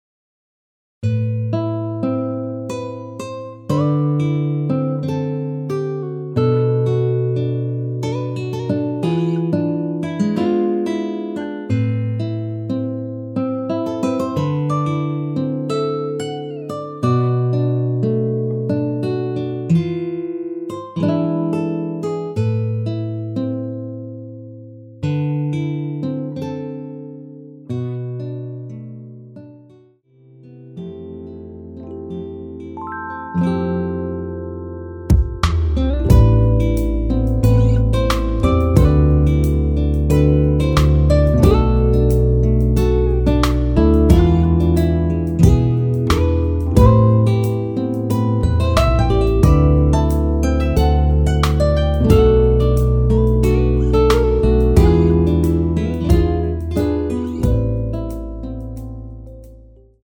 Am
앞부분30초, 뒷부분30초씩 편집해서 올려 드리고 있습니다.